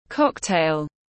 Rượu cốc-tai tiếng anh gọi là cocktail, phiên âm tiếng anh đọc là /ˈkɒk.teɪl/
Cocktail /ˈkɒk.teɪl/